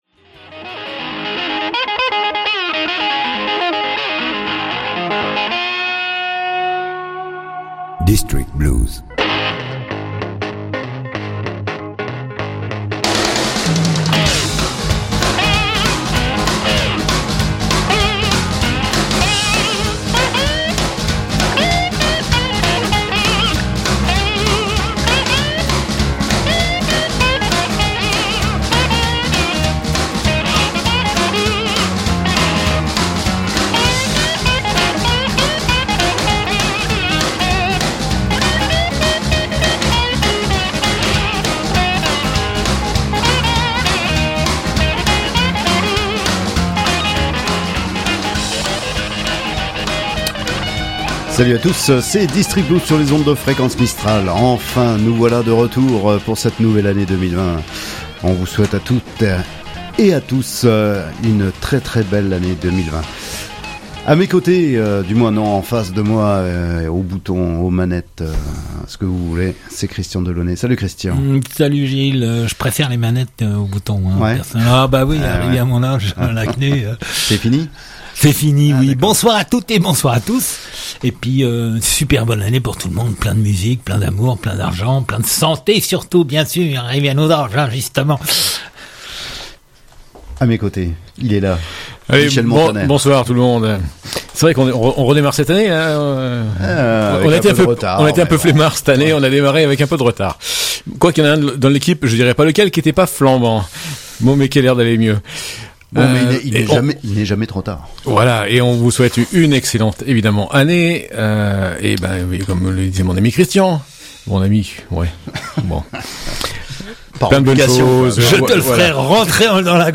qu’il soit roots, swamp, rock ou du delta
une émission radio